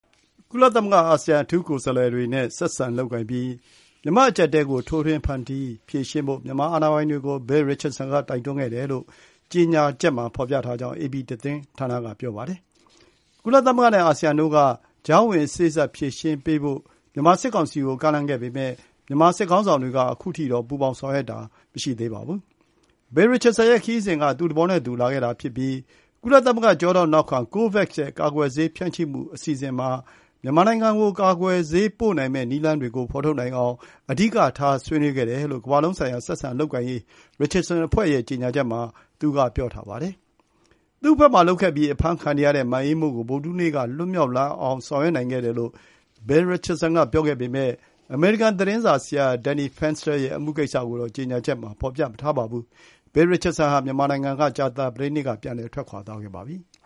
ဒီခရီးစဉျနဲ့ပတျသကျပွီး စဈကောငျစီပွောခှင့ျရ ဗိုလျခြုပျဇောျမငျးထှနျးက ဗှီအိုအကေို အခုလိုပွောပါတယျ။